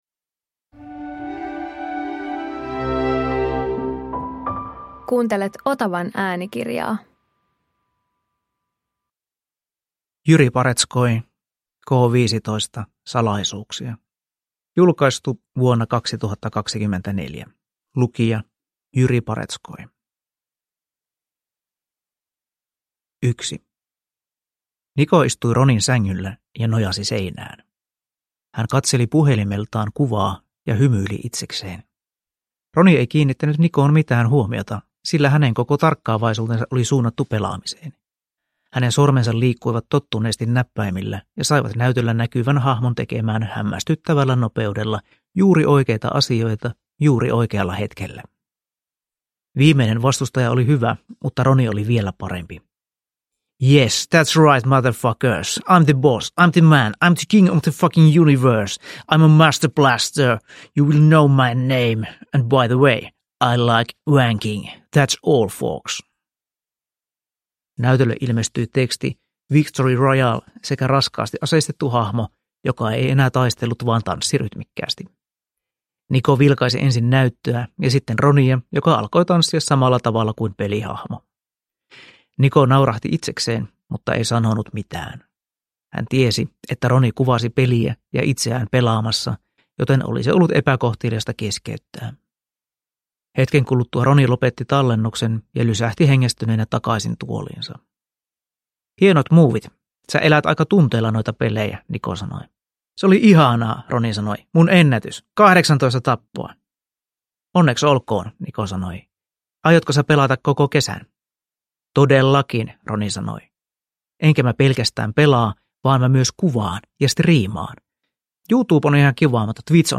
K15 - Salaisuuksia – Ljudbok